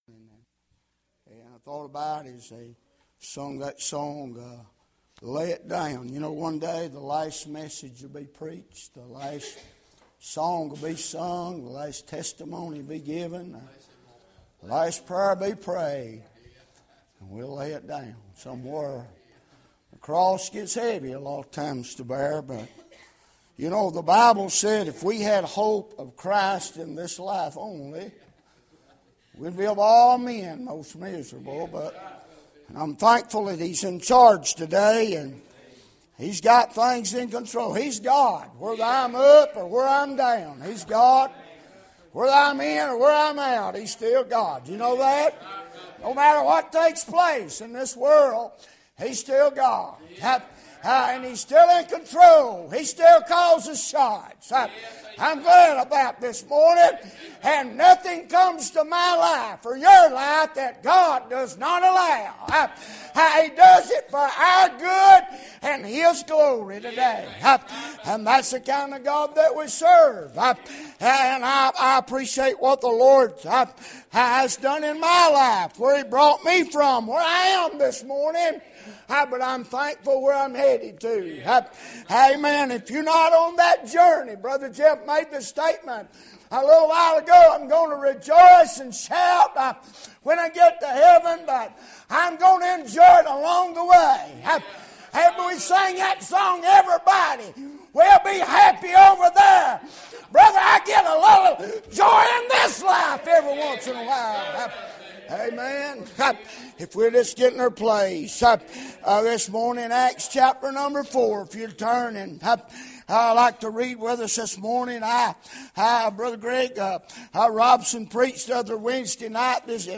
Sermon media